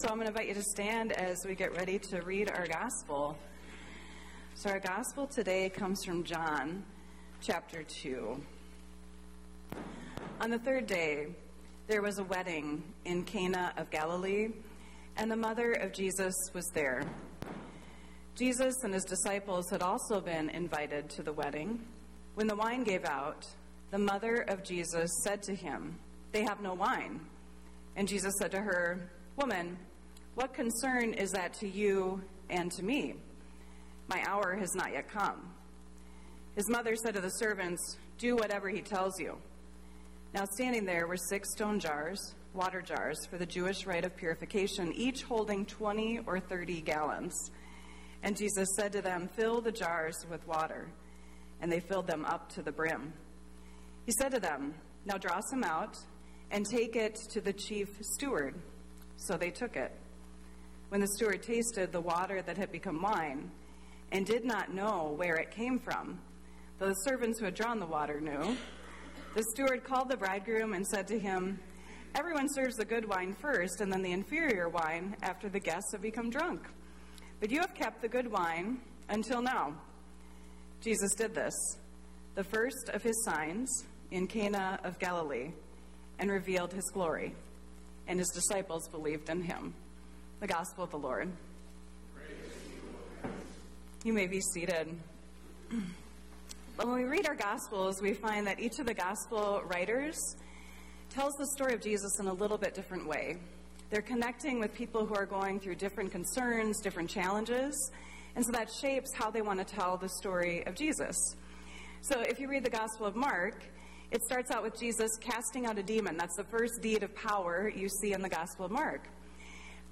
Sermon Podcast "Withness"